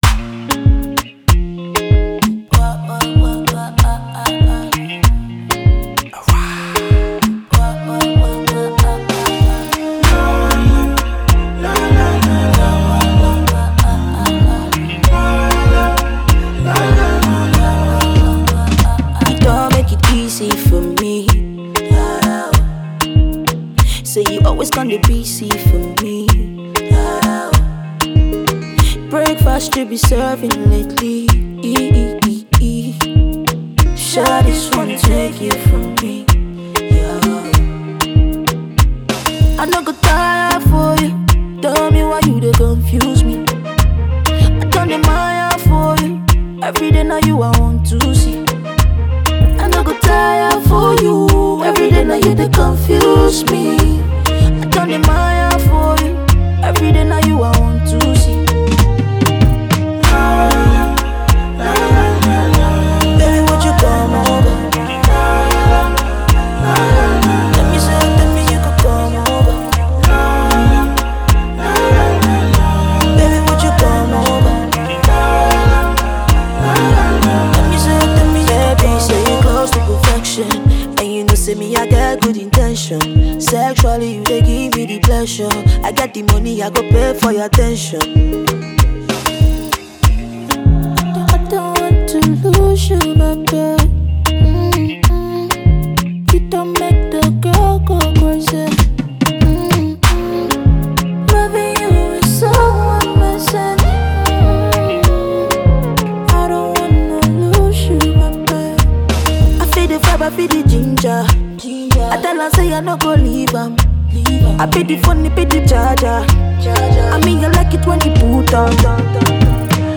Nigerian female vocalist & Songwriter
RnB drill record